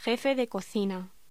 Locución: Jefe de cocina